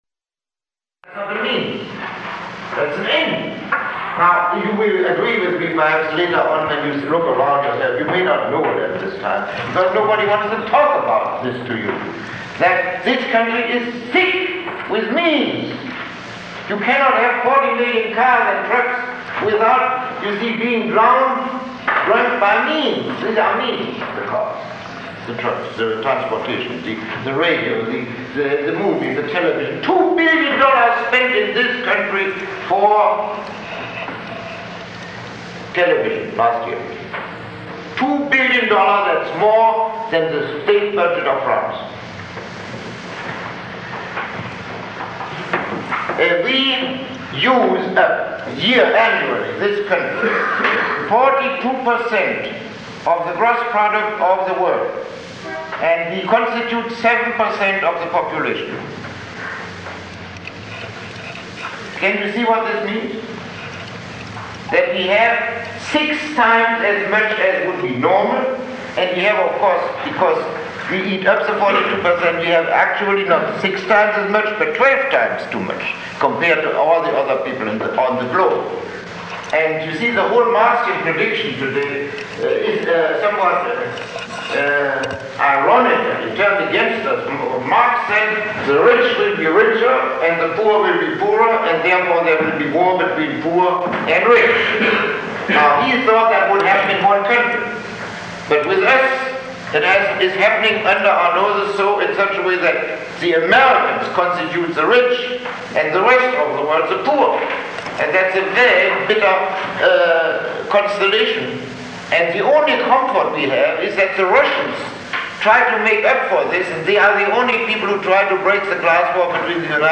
Lecture 01